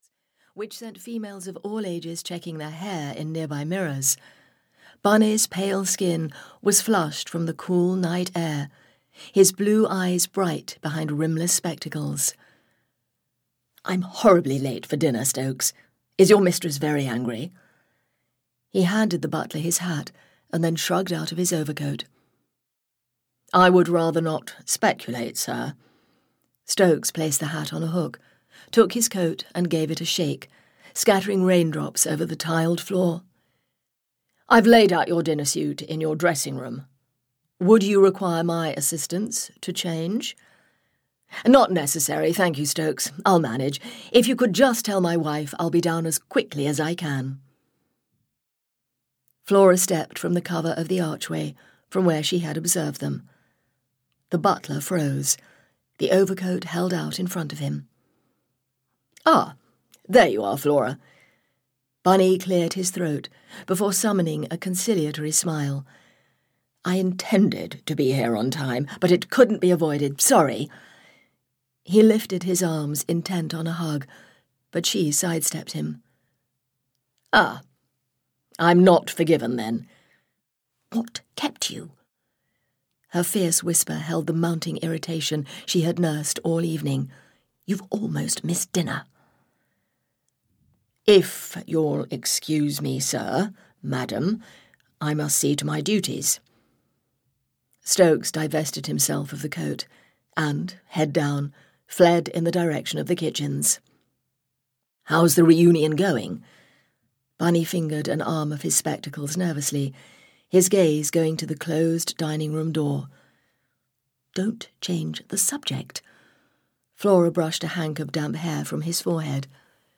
Death on a Train (EN) audiokniha
Ukázka z knihy